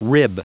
Prononciation du mot rib en anglais (fichier audio)
Prononciation du mot : rib